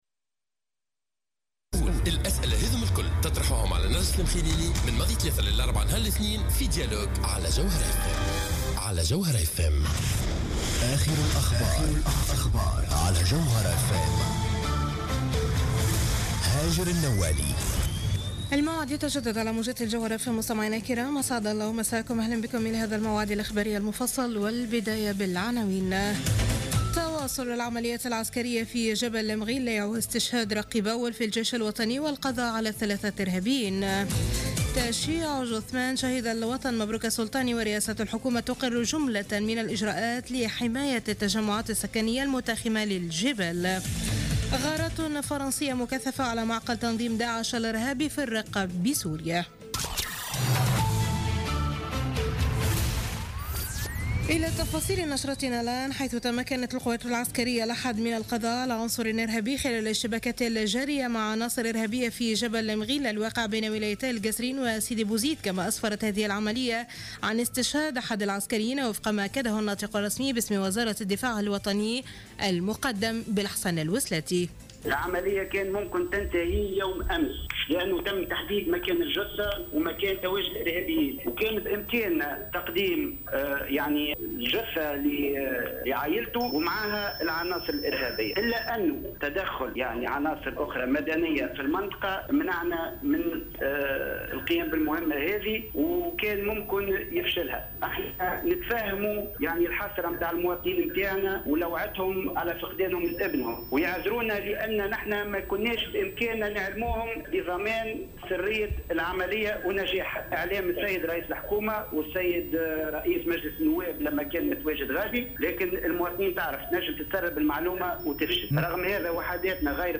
نشرة أخبار منتصف الليل ليوم الأحد 15 نوفمبر 2015